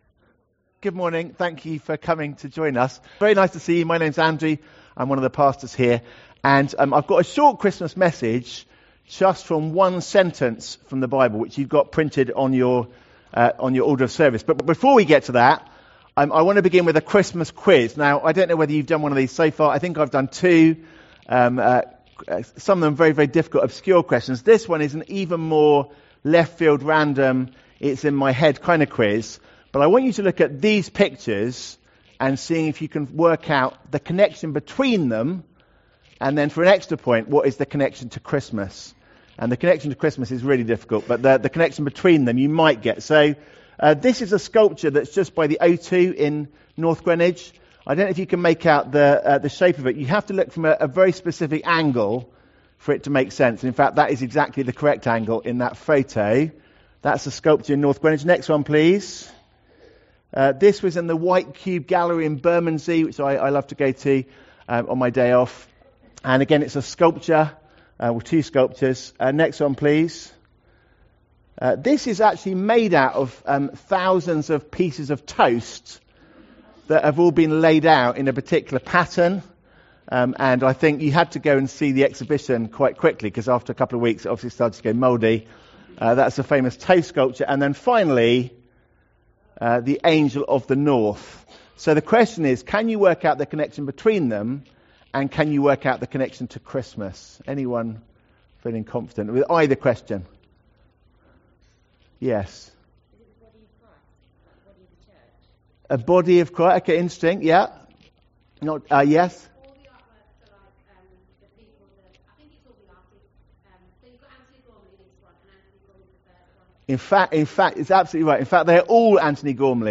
Traditional Carol Service 2025 – Grace Church Greenwich